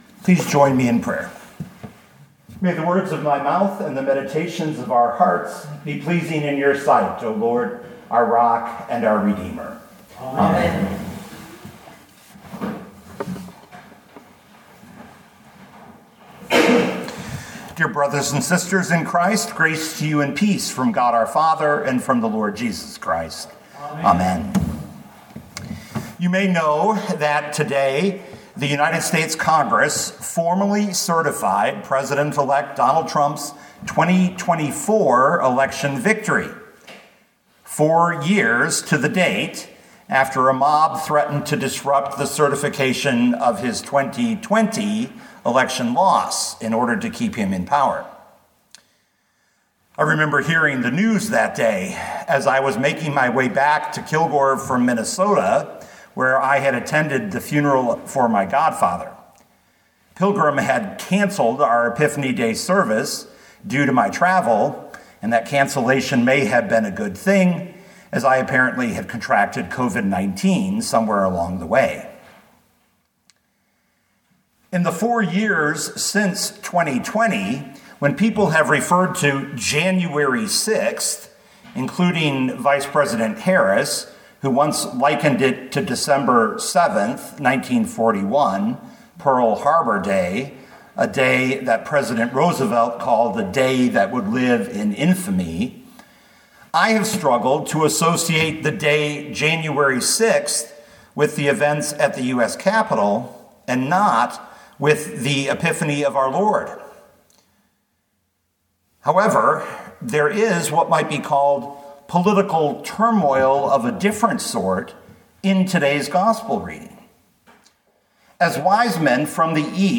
2025 Matthew 2:1-12 Listen to the sermon with the player below, or, download the audio.